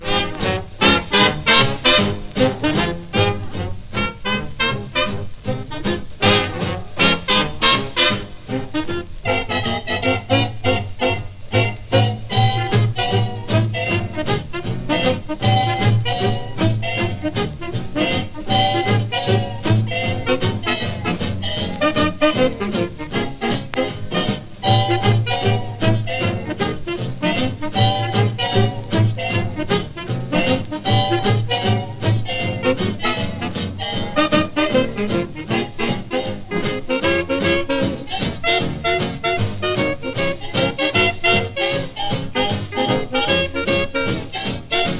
Jazz, Swing, Big Band　USA　12inchレコード　33rpm　Mono